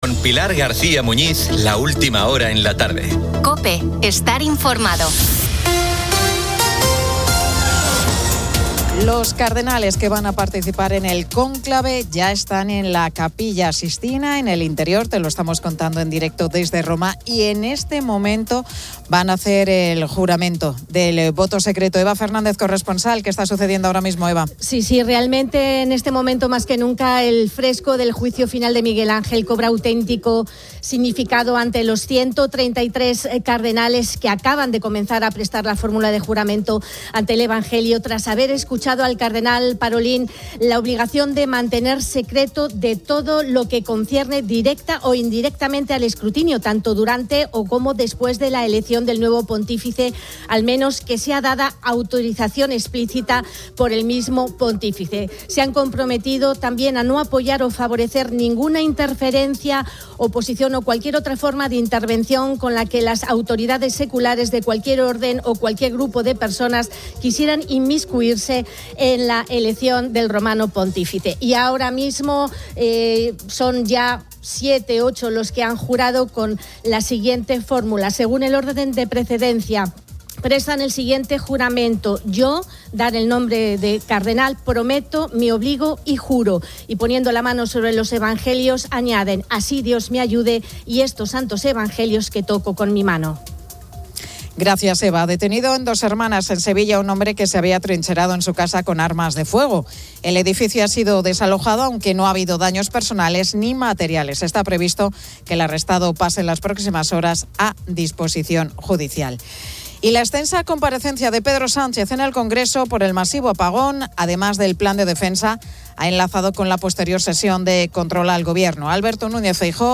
La Tarde 17:00H | 07 MAY 2025 | La Tarde Pilar García Muñiz sigue en directo desde Roma el juramento de los cardenales y el 'extra omnes' previo al inicio del cónclave para la elección de un nuevo Papa.